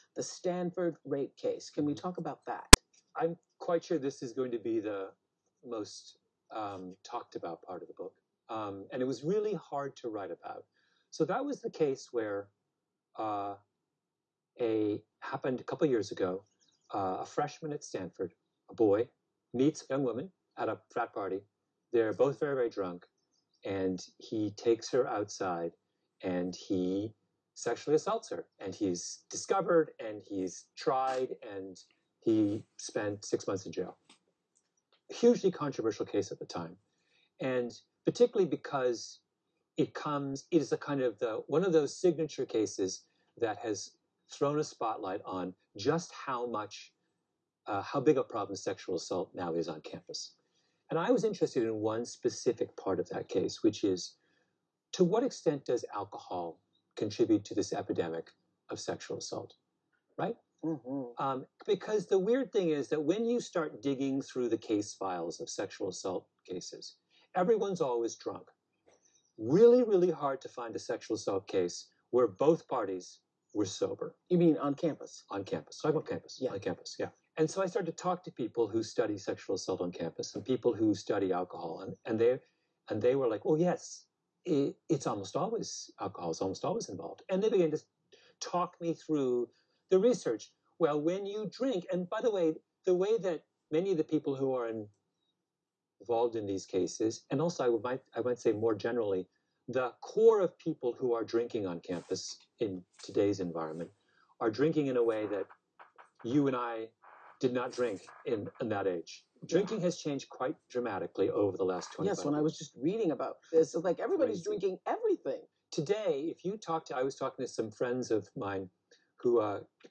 I was listening to Malcomb Gladwell being interviewed by Oprah Winfrey.